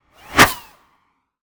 bullet_flyby_10.wav